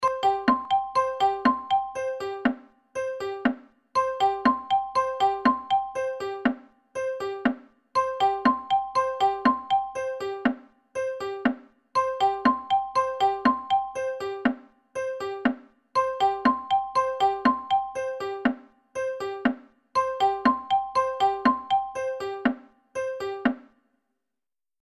جلوه های صوتی
دانلود صدای ساعت 5 از ساعد نیوز با لینک مستقیم و کیفیت بالا